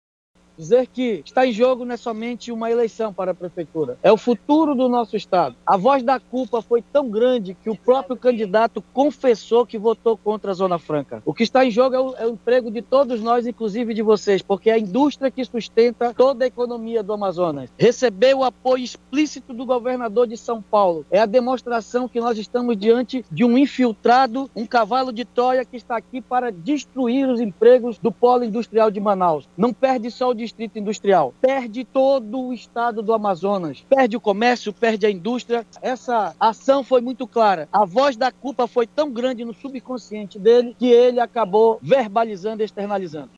Ao chegar ao colégio eleitoral conversou com a imprensa e relatou que a votação coloca em jogo o futuro de Manaus, os empregos, a economia.